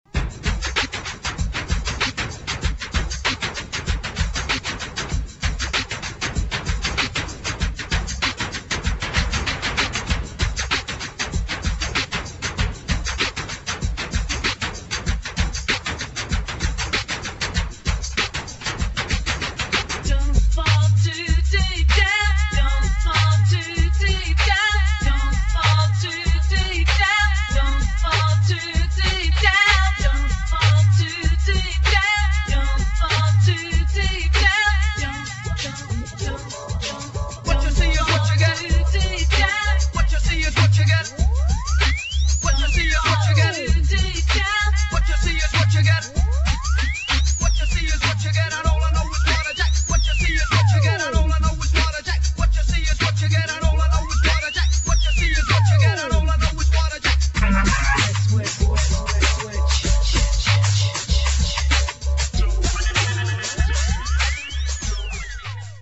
[ ROCK / BIG BEAT / BREAK BEAT ]